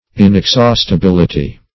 Search Result for " inexhaustibility" : The Collaborative International Dictionary of English v.0.48: Inexhaustibility \In`ex*haust`i*bil"i*ty\, n. The state or quality of being inexhaustible; abundance.